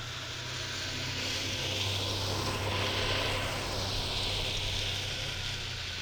Snowmobile Description Form (PDF)
Subjective Noise Event Audio File (WAV)
sdsmt_electric_2007.wav